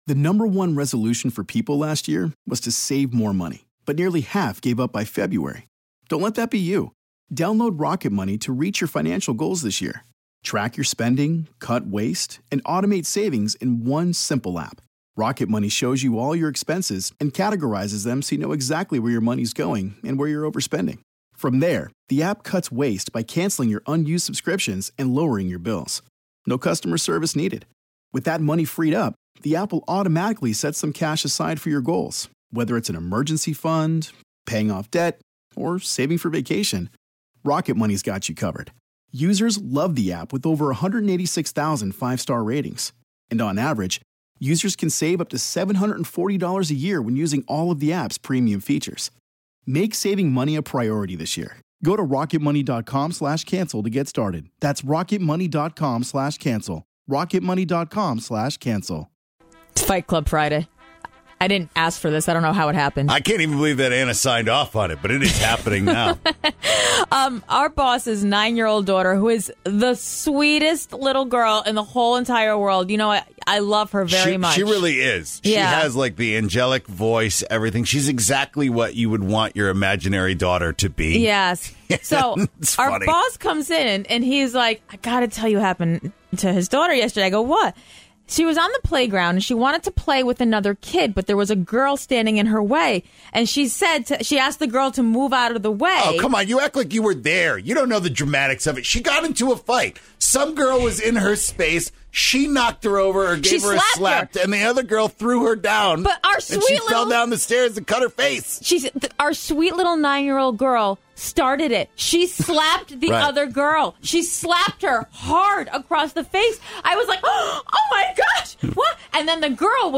and hear about some crazy school fights from callers!